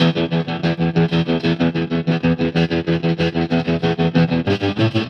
Trem Trance Guitar 01c.wav